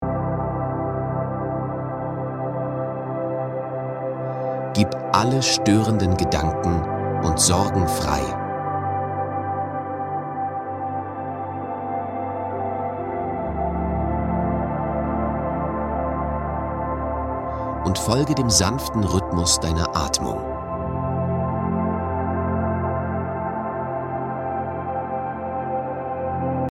männlicher Stimme und mit weiblicher Stimme.
Probe-maennlich.mp3